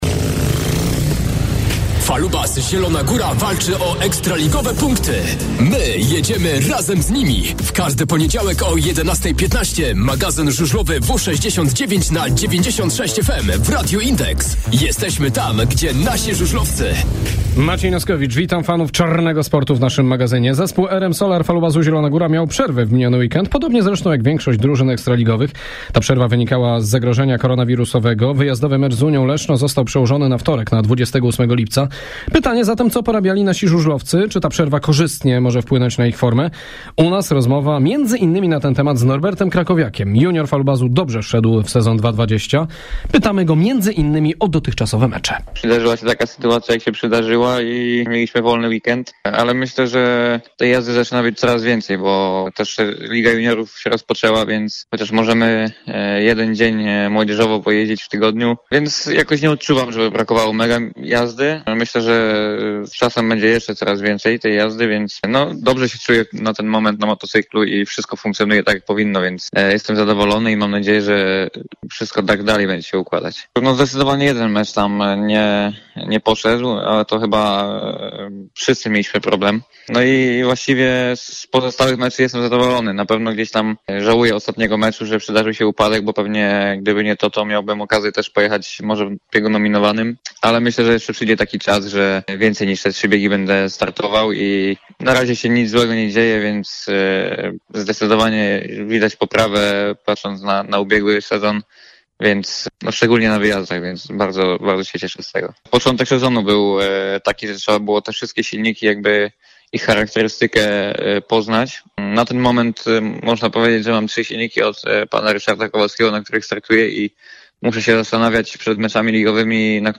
Zapraszamy na magazyn żużlowy Radia Index “W69 na 96FM”. Dziś w programie wykorzystujemy przerwę, która w miniony weekend mieli żużlowcy RM Solar Falubazu Zielona Góra.